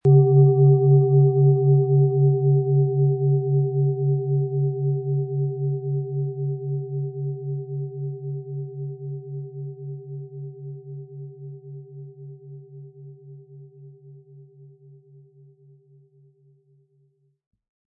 Planetenton 1
Planetenschale® Geistige Wachheit & Nachdenken und Verstehen können mit Merkur, Ø 17,8 cm inkl. Klöppel
SchalenformBihar
MaterialBronze